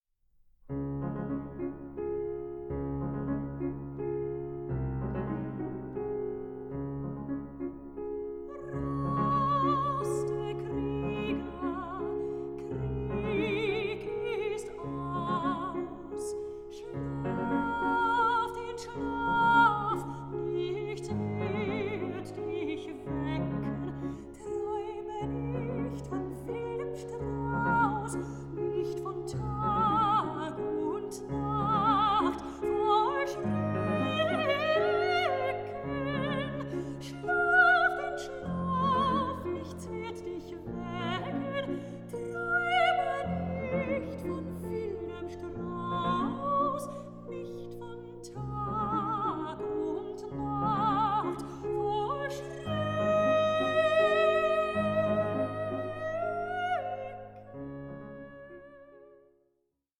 A VOCAL EXPLORATION OF HOMESICKNESS